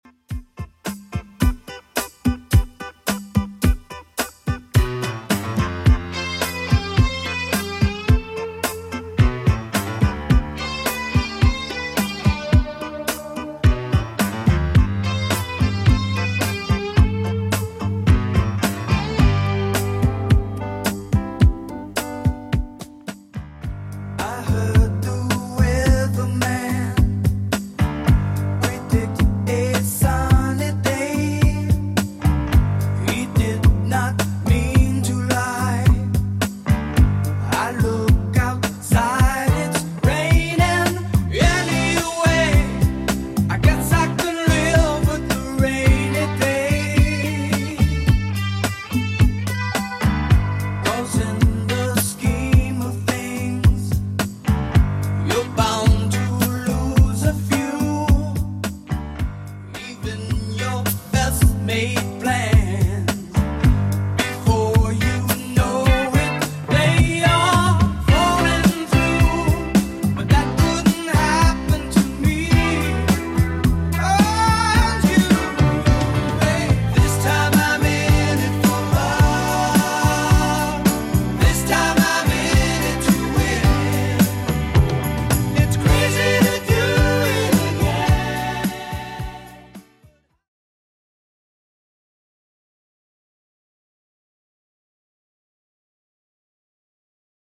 Genre: 70's